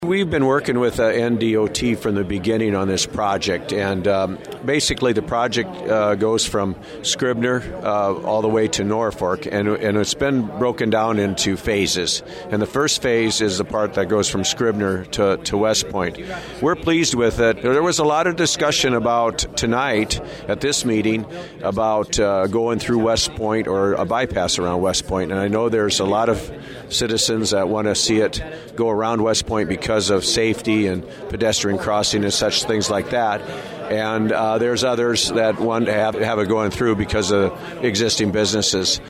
U.S. Army Corps of Engineers and Nebraska Department of Transportation Officials gave a presentation at Mohr Auditorium in Scribner Wednesday night regarding the proposed Highway 275 Expressway Project from West Point to Scribner. The idea is to use a 2+2 concept and widen the roadway East and West from two miles north of West Point to two miles south of Scribner.